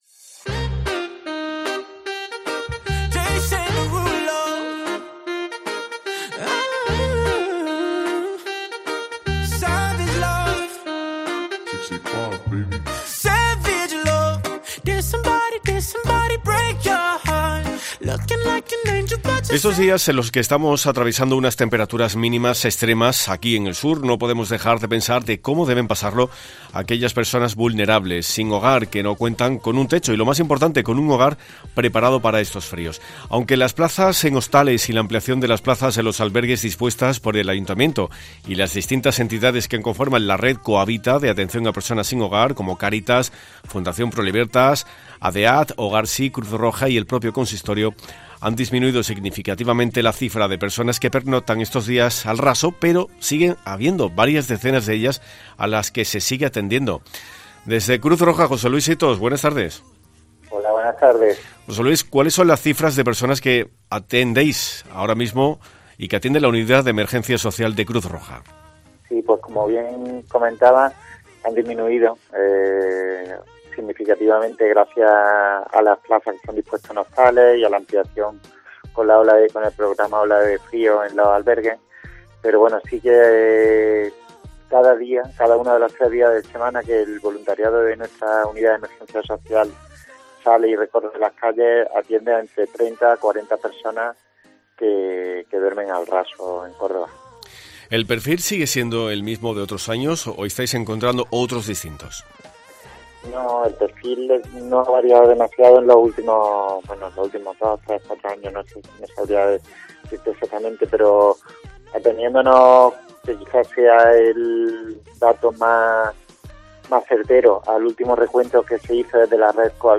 Hoy en Mediodía COPE nos hemos centrado en hablar con Cruz Roja Córdoba y Cáritas para conocer cúal es el trabajo que están desarrollando y a qué número de personas están atendiendo.